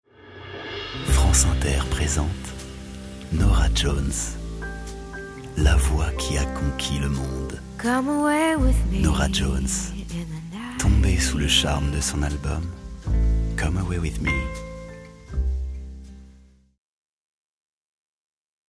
Sprecher französisch Voix off tonique, médium-grave, ou autre selon votre demande ...
Sprechprobe: eLearning (Muttersprache):